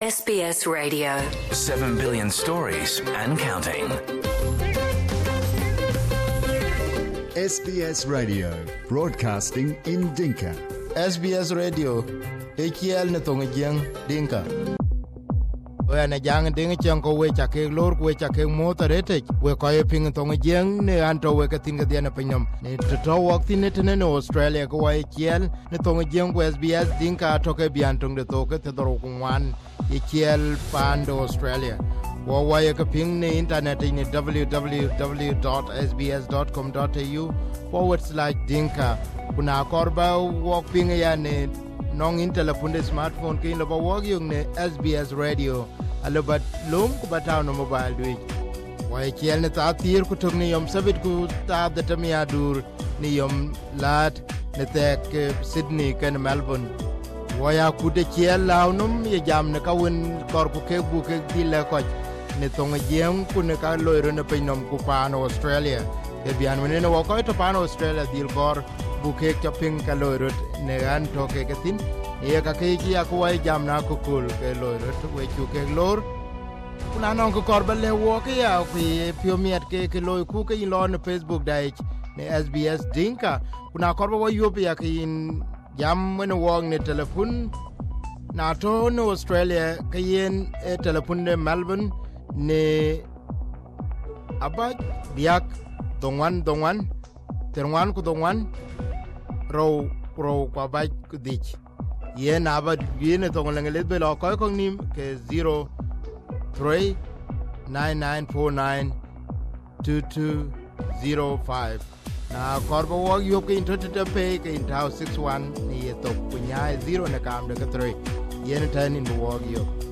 This is a report about the sacking of Governor of Northern Bhar el Gazal Hon Kuel Aguer Kuel.